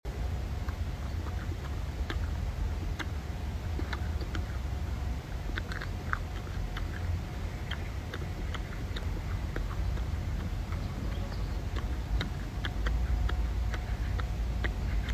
Insect Feast#InsectFeast#insect#Edibleinsect#Green#Healthyfood#Highprotein#Foodie#mukbang#asmr#eatingshow#fpy#trending